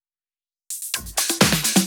Fill 128 BPM (40).wav